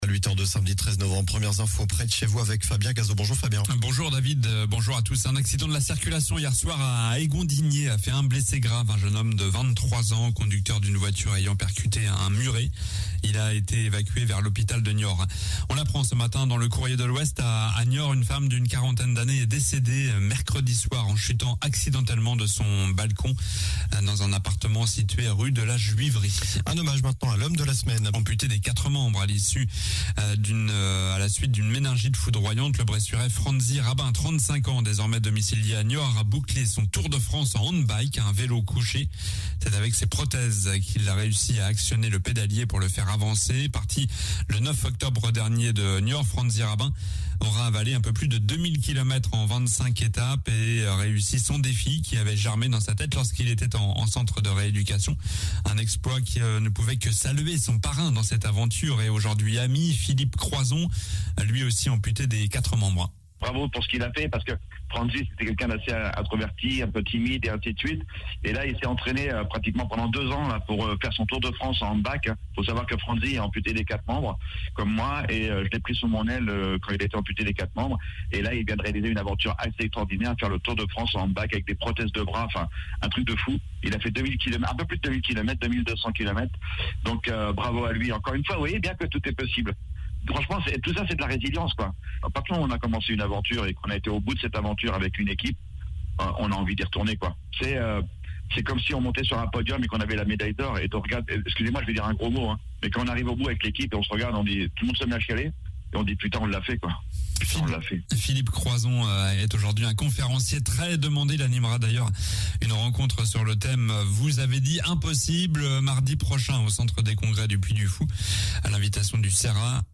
Journal du samedi 13 novembre